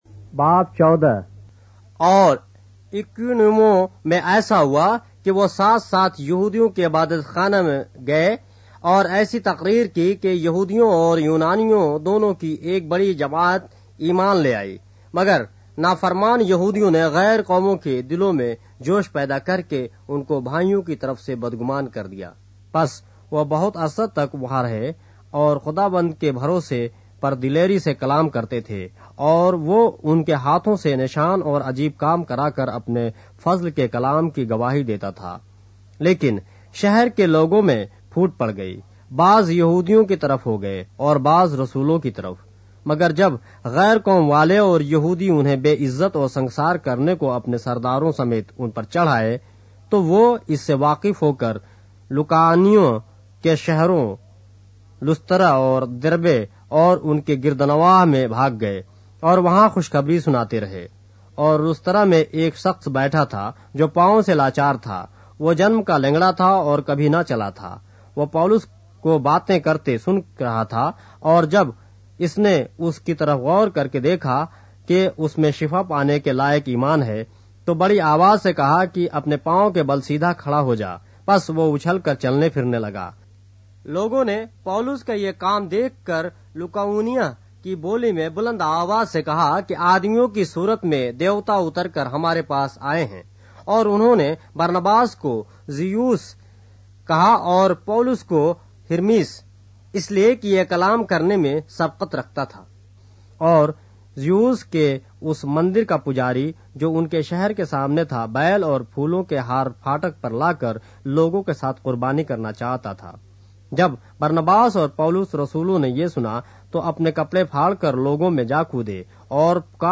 اردو بائبل کے باب - آڈیو روایت کے ساتھ - Acts, chapter 14 of the Holy Bible in Urdu